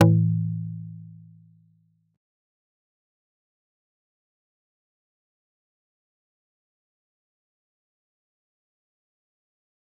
G_Kalimba-A2-pp.wav